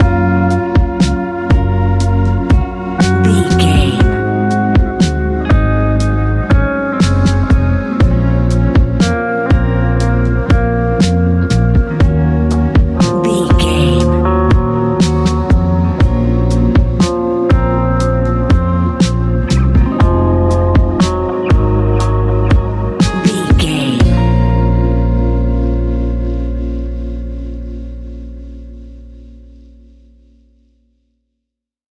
Ionian/Major
A♯
chill out
laid back
Lounge
sparse
new age
chilled electronica
ambient
atmospheric